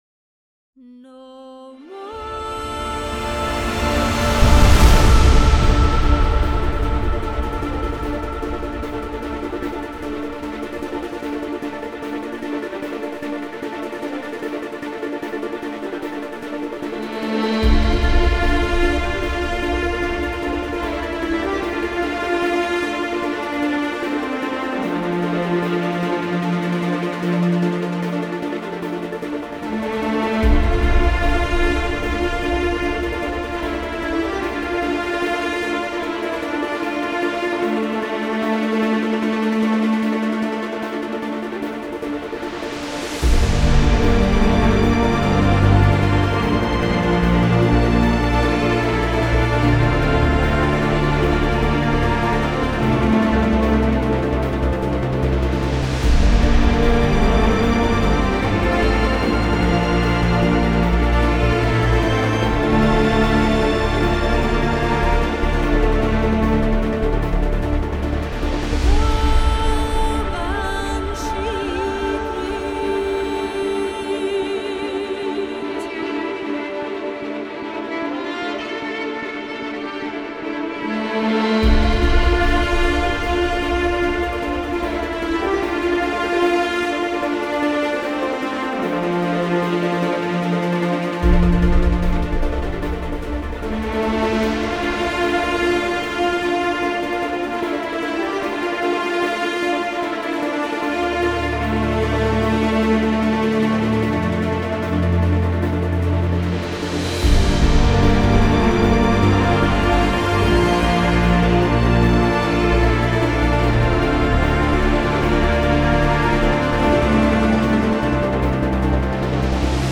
آموریم و همسرش اینگونه منچستر را ترک کردند / فیلم برچسب‌ها: موسیقی حماسی موسیقی بیکلام موسیقی فاخر orion دیدگاه‌ها (اولین دیدگاه را بنویسید) برای ارسال دیدگاه وارد شوید.